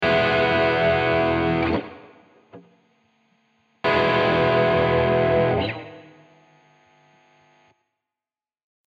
Die erste Greco Early Sixties (Bj. 1977) ist mit NRS, die zweite (Bj. 1979) ohne. Man muss ziemlich laut hören und hört das Rauschen nur ganz am Ende.